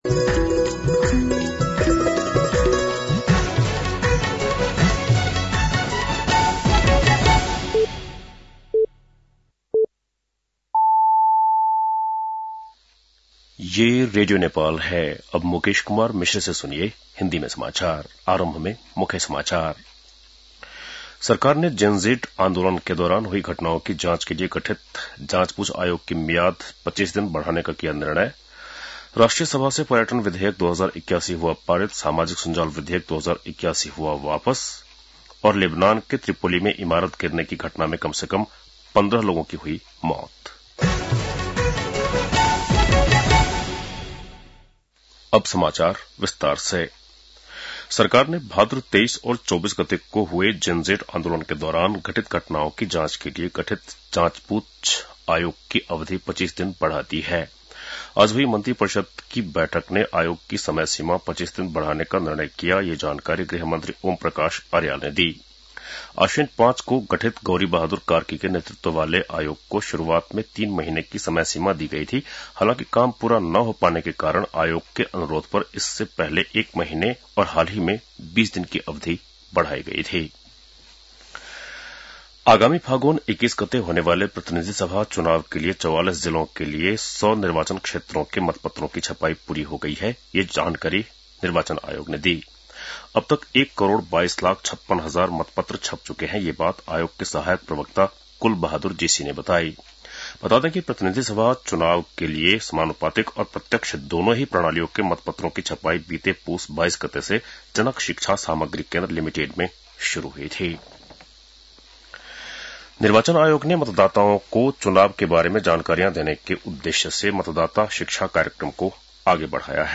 बेलुकी १० बजेको हिन्दी समाचार : २६ माघ , २०८२